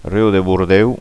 Bordèu Prononcer "Bourdèw".